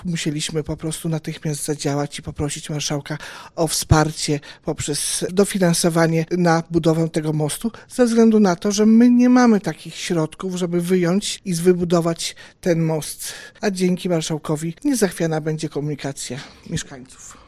Musieliśmy natychmiast zadziałać aby nie utrudniać ruchu drogowego, mówi wójt Gminy Andrzejewo, Beata Urszula Ponichtera.